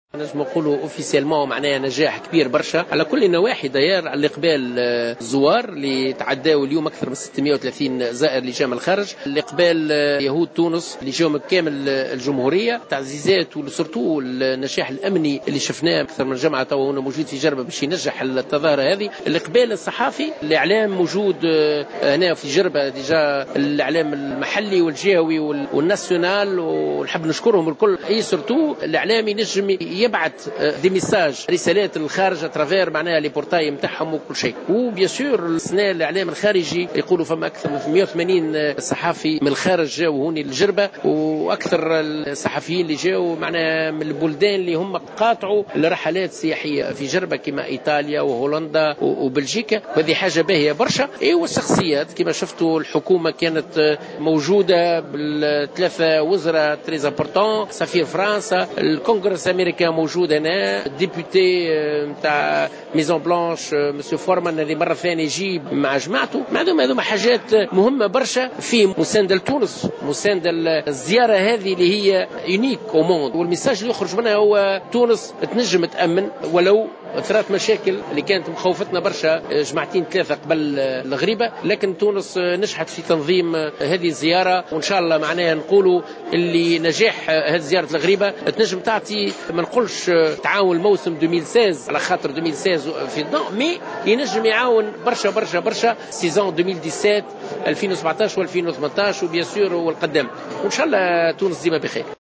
Dans une déclaration accordée à la correspondante de Jawhara FM, René Trabelsi, organisateur des voyages vers la Ghriba a affirmé que le pèlerinage de la Ghriba de cette année est réussi.